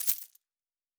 pgs/Assets/Audio/Fantasy Interface Sounds/Coins 04.wav at master
Coins 04.wav